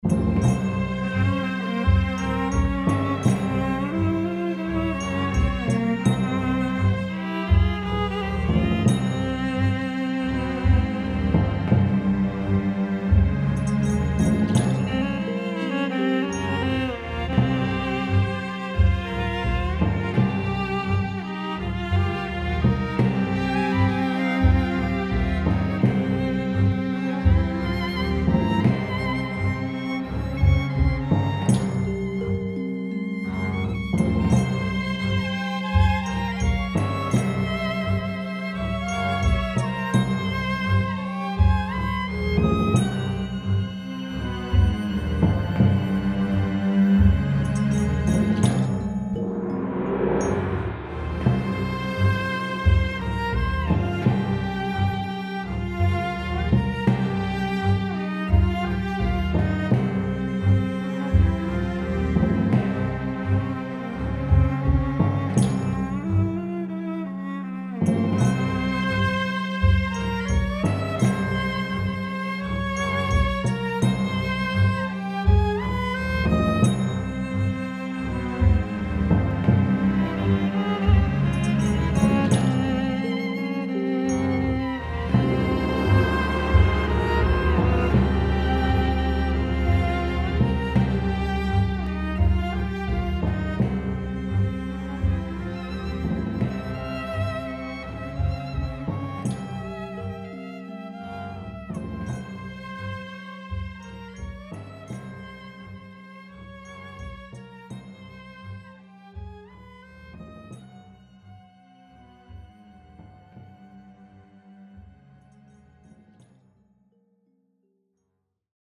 Caravan-Ambient.mp3